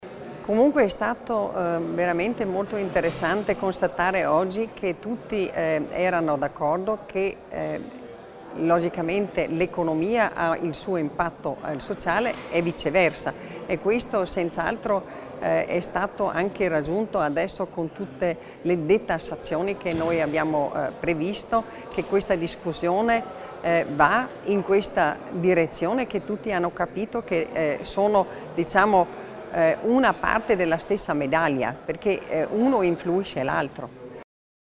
L'Assessore Stocker spiega il connubio tra economia e sociale